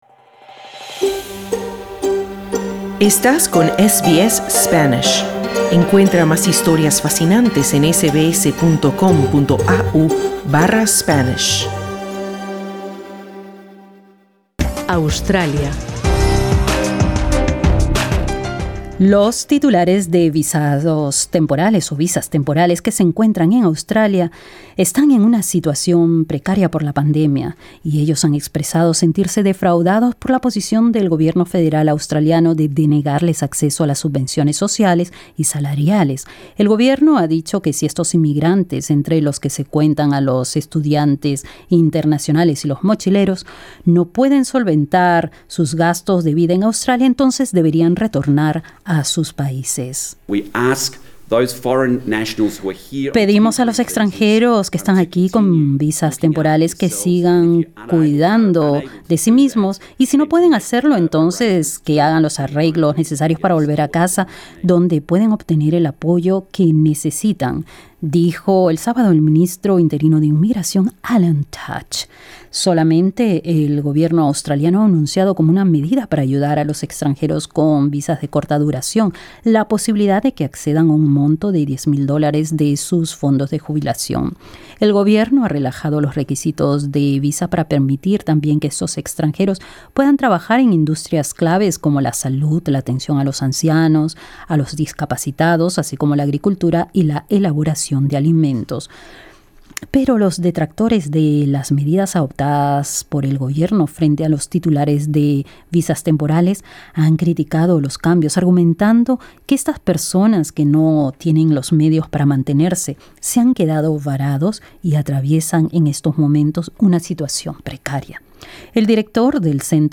También instó, durante una entrevista con SBS Spanish, a los afectados a no esperar hasta el último minuto para resolver su estatus migratorio.